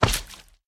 Sound / Minecraft / mob / magmacube / big3.ogg